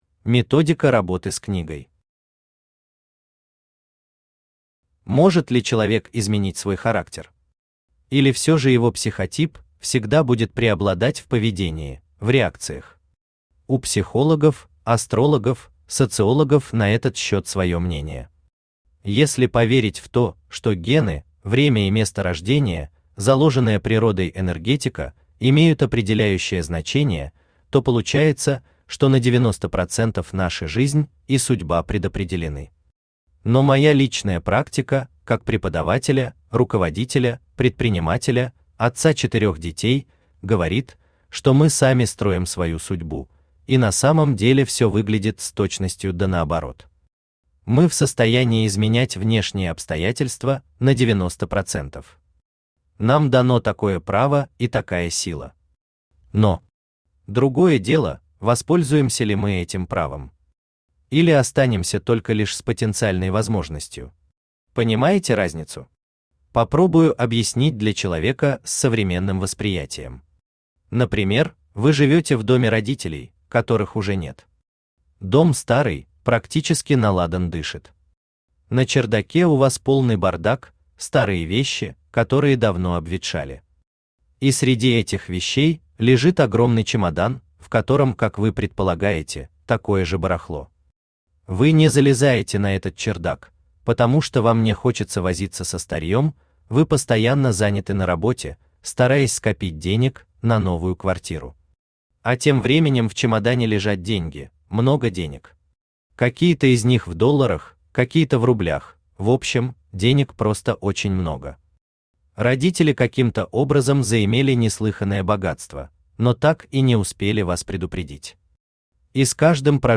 Aудиокнига Сборник самотренингов, или Управление собой и результатом в продажах и переговорах Автор Вячеслав Александрович Егоров Читает аудиокнигу Авточтец ЛитРес.